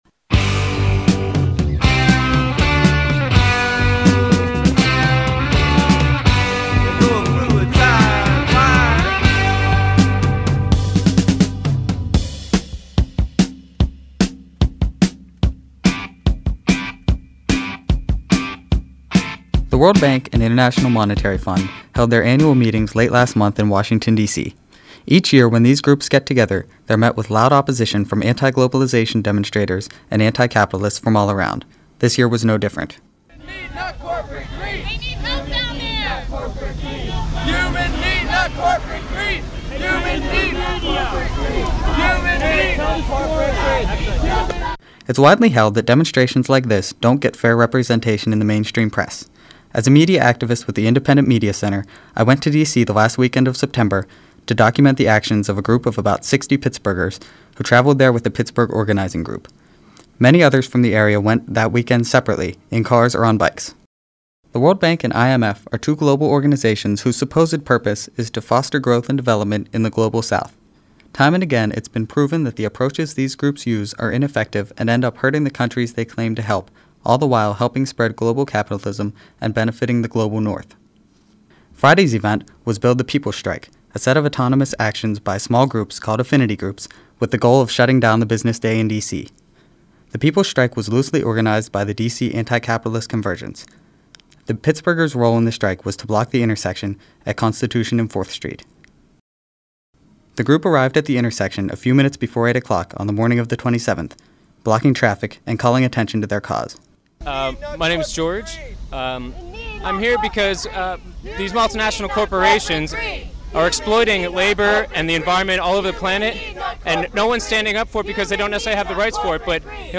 protests_mix1_fadeout.mp3